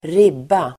Uttal: [²r'ib:a]